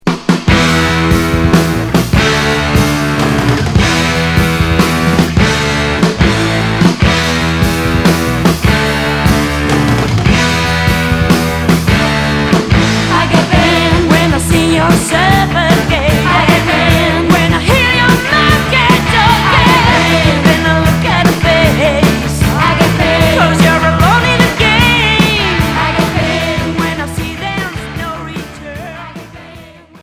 Hard 45t promo en pochette générique retour à l'accueil